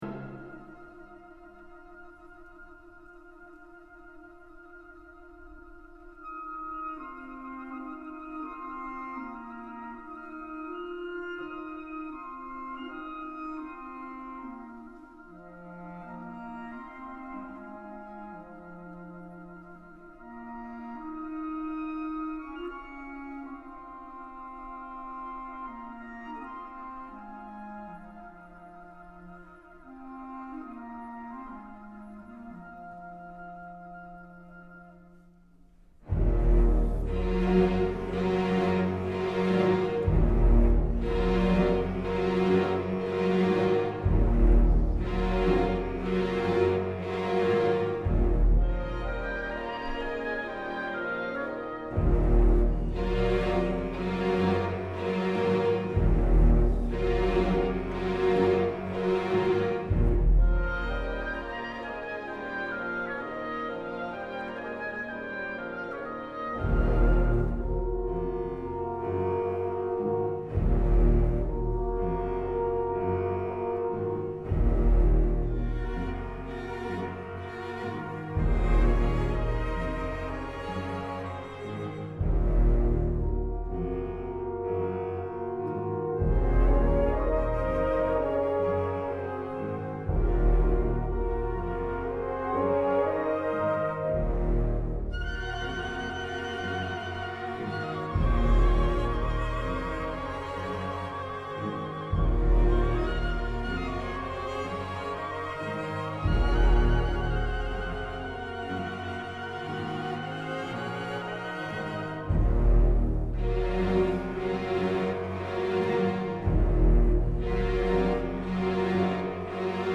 Heel ruimtelijk.
met die hele lage bassen.